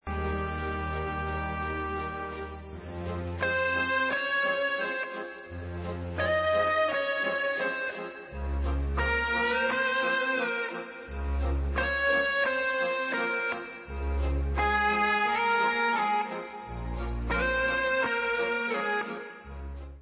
1996 studio album w.
Pop/Symphonic